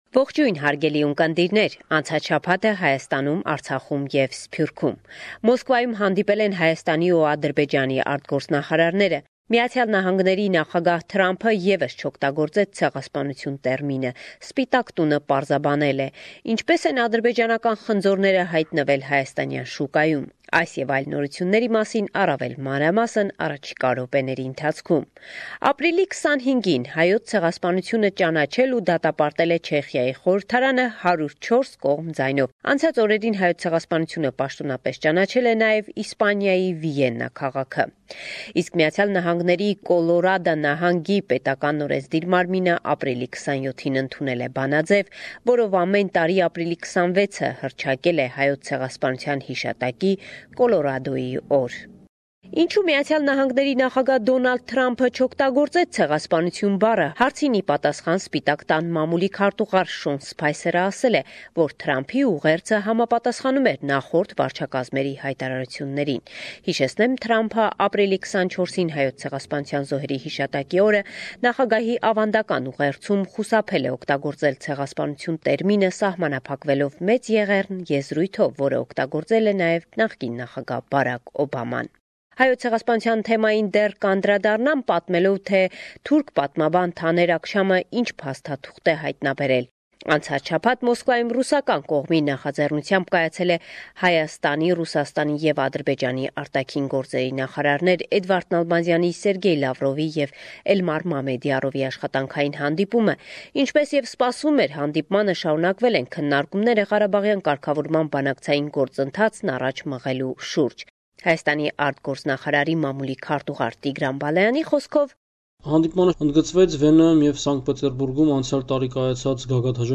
Latest News – 2 May 2017